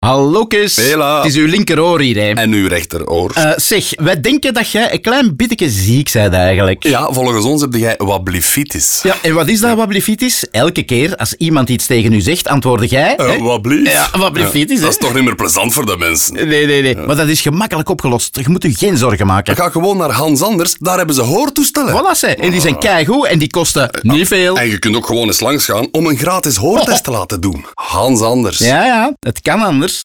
Om de awareness rond de hoortoestellen van Hans Anders te verhogen, creëerde mortierbrigade een radioconcept waarbij je eigen oren je aanspreken.